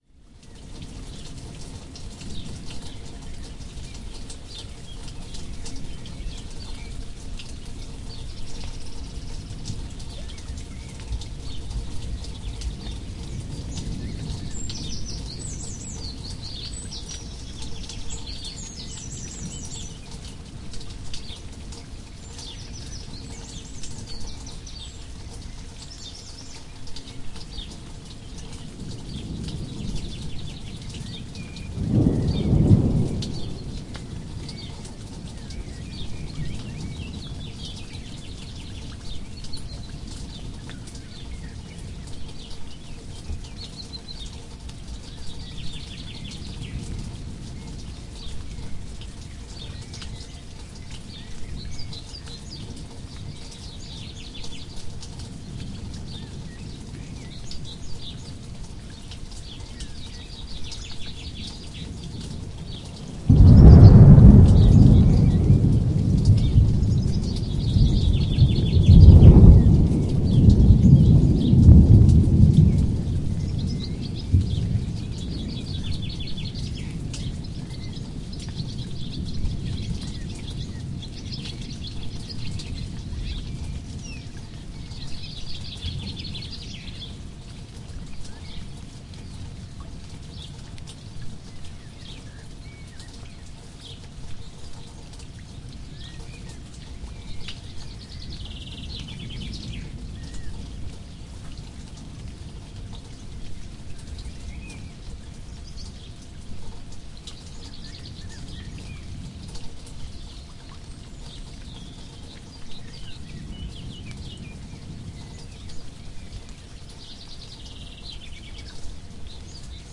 描述：......几场雨 ，更多的鸟和雷声远......
Tag: 声音 自然 souinscape 环境 风暴